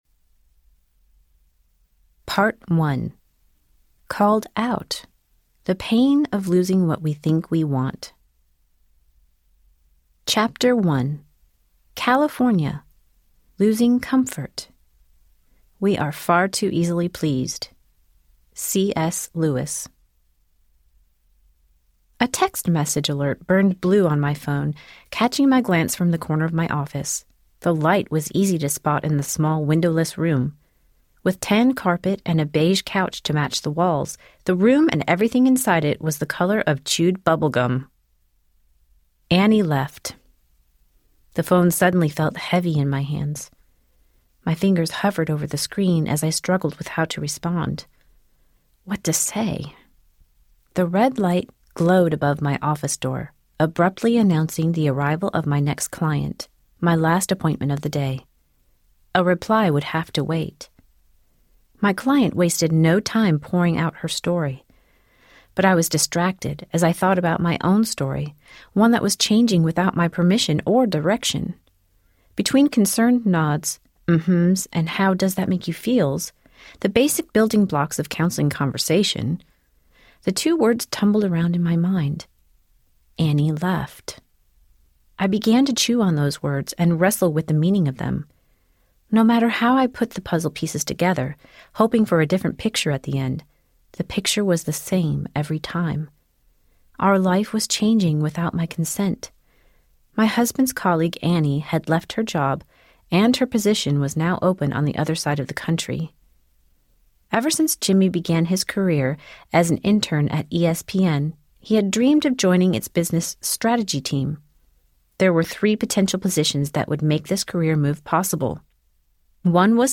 From Lost to Found Audiobook
Narrator
6.3 Hrs. – Unabridged